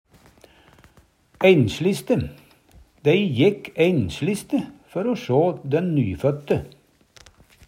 DIALEKTORD PÅ NORMERT NORSK einsliste eins ærend Eksempel på bruk Dei jekk einsliste før o få sjå dæn nyføtte.